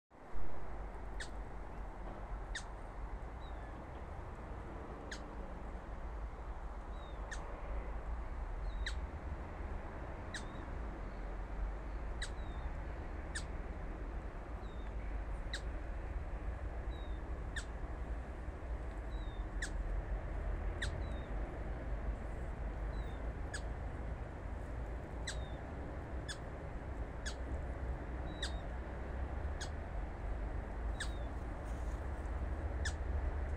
Little Crake, Zapornia parva
Notes/novērots ad T un vismaz divi melni cāļi, uz provokāciju T atbild ar uztraukuma saucienu.